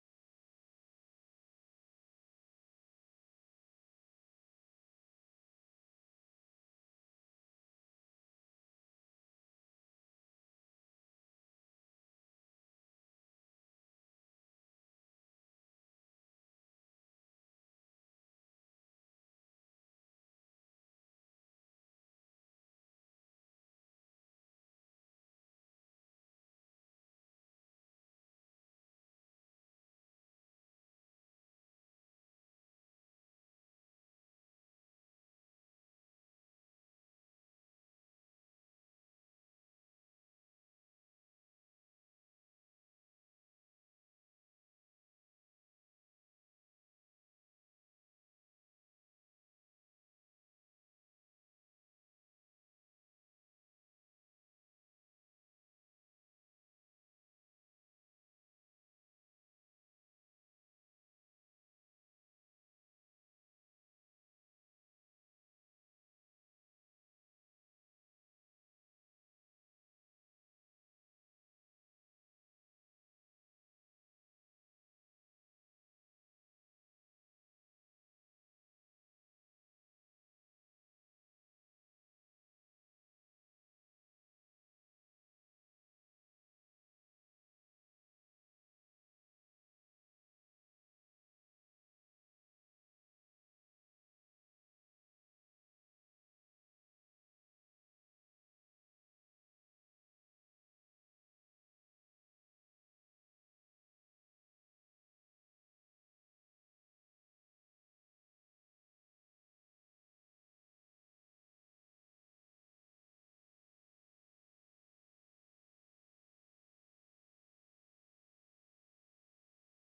Засідання Комітету від 18 березня 2026 року